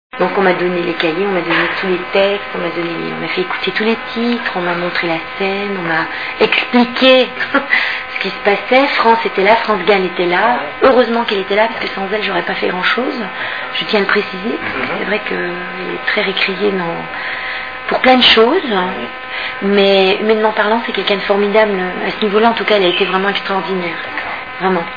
( Zénith de Lille, tournée de Roméo & Juliette, le 25/05/2001 )